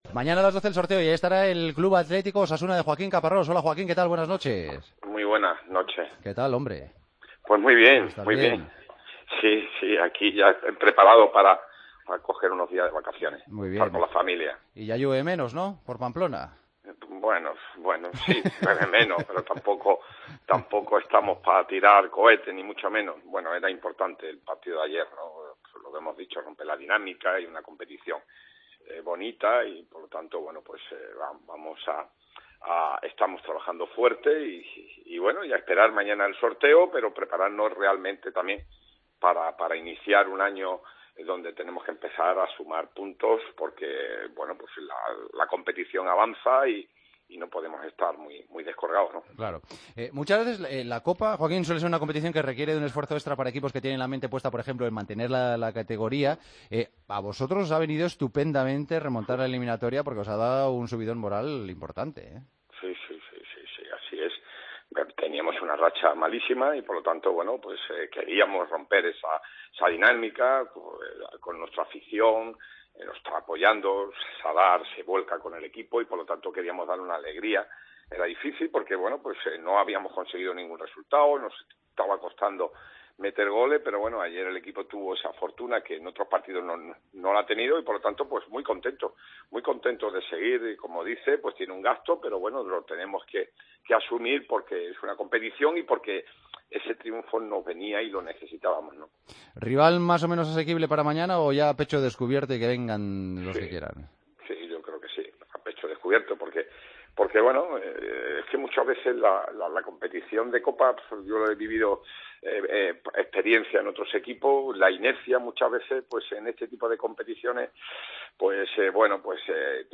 Charlamos con el técnico de Osasuna, que este viernes estará en el bombo de los octavos de Copa: "Tenemos que empezar a sumar puntos porque no podemos quedarnos muy descolgados.